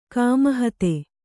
♪ kāmahate